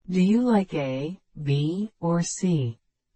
ちなみに、or を使った疑問文では、最後だけイントネーションが下がります。
Do you like A, B, or C ? ではA↑, B↑, or C↓ というイントネーションになります。